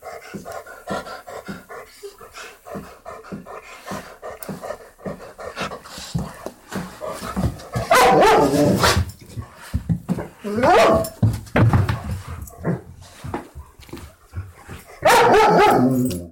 对不起，我录制的没有风/弹幕，但它是双耳的。
标签： 来讲 动物 演讲 狗的哀鸣 语言 动物
声道立体声